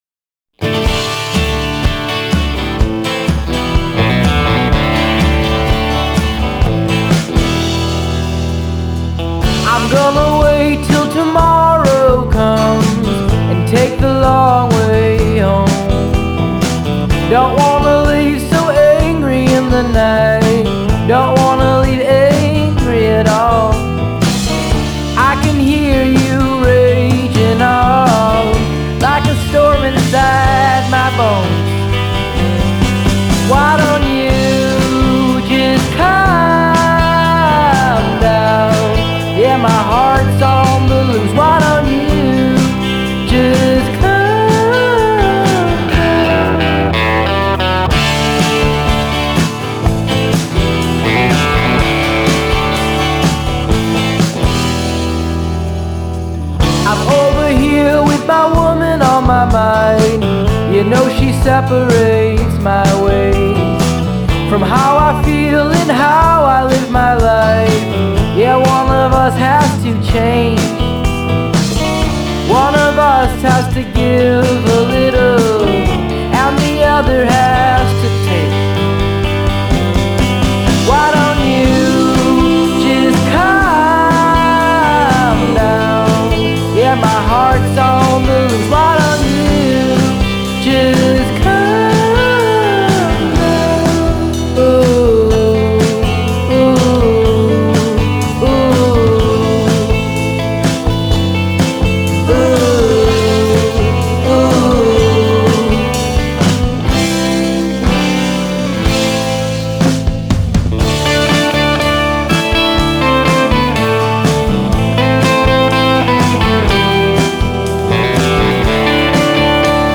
Genre: Alt Folk, Americana